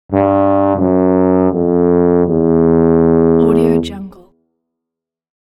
Sad Tuba Sound Button - Free Download & Play